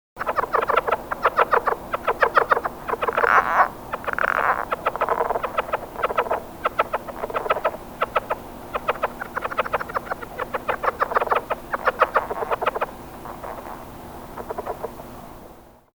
Lowland Leopard Frog - Lithobates yavapaiensis
The call of the Lowland Leopard Frog consists of several short quiet chuckles, sounding like quick, short, kisses.
The frogs called from an artificial pond which is part of a joint effort to re-establish Lowland Leopard Frogs near Saguaro National Park in areas where they were once more common.
Sound This is a 16 second recording of a small chorus of frogs.